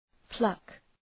{plʌk}
pluck.mp3